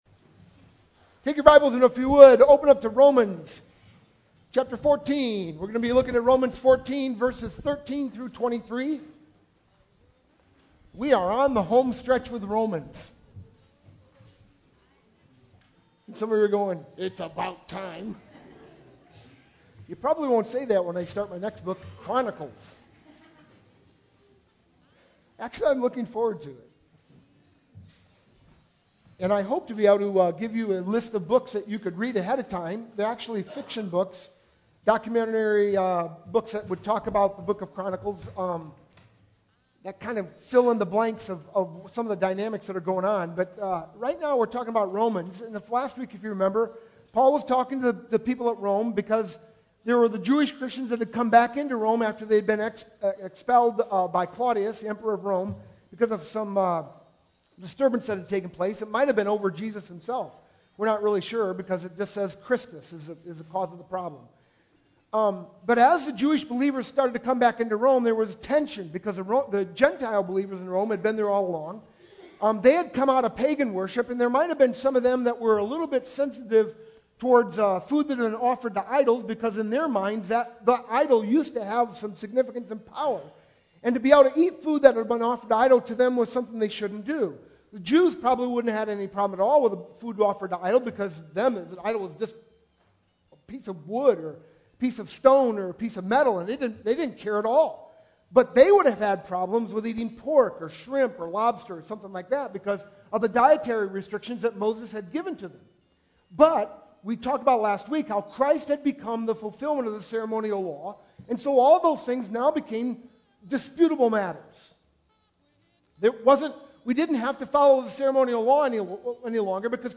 sermon-2-12-12.mp3